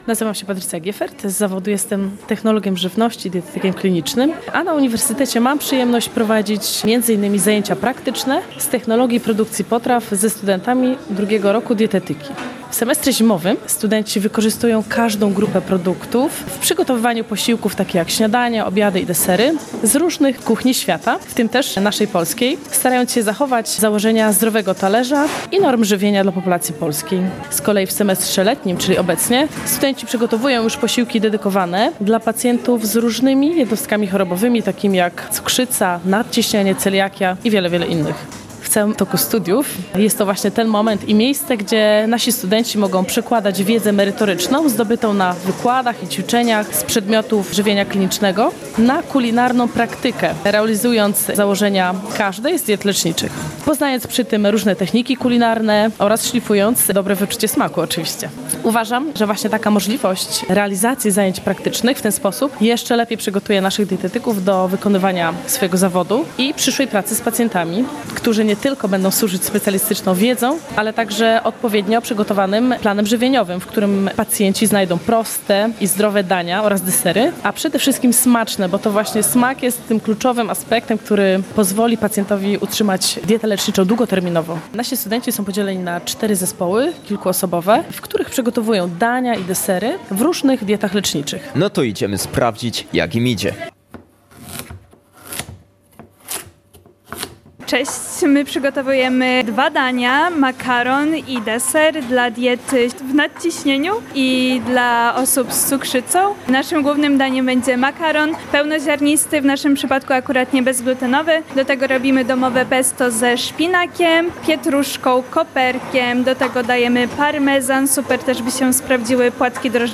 Zajęcia praktyczne na kierunku Dietetyka – relacja z Uniwersytetu Rzeszowskiego